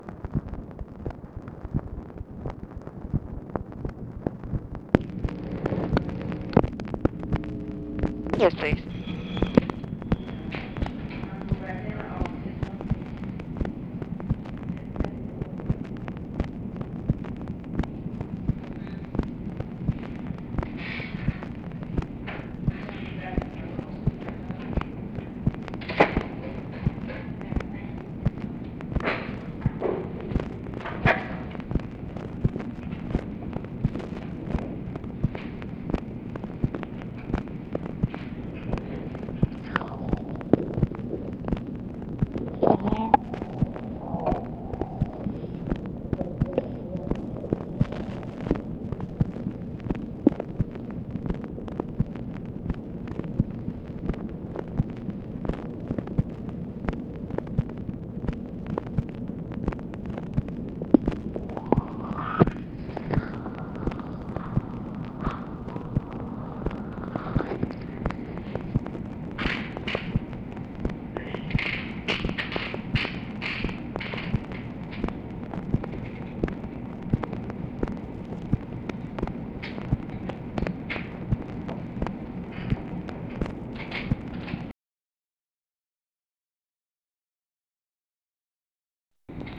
"YES, PLEASE" ONLY INTELLIGIBLE WORDS
Conversation with OFFICE CONVERSATION
Secret White House Tapes | Lyndon B. Johnson Presidency